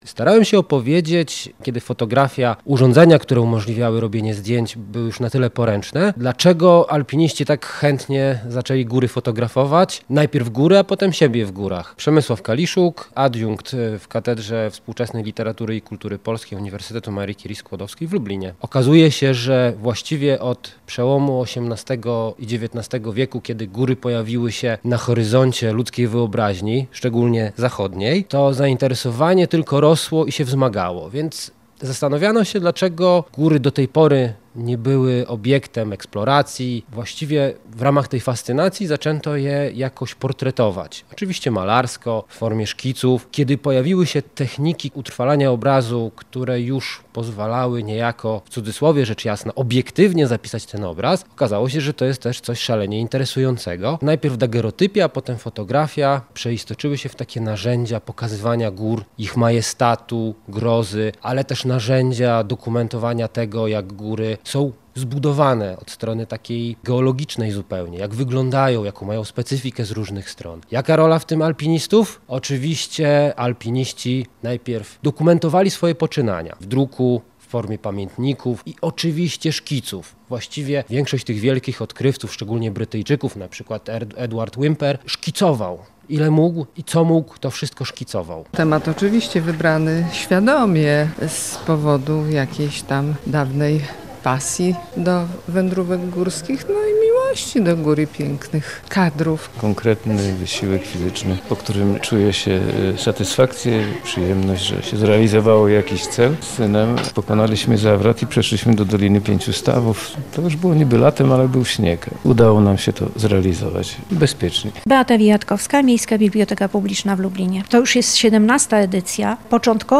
Wykład Góry i fotografie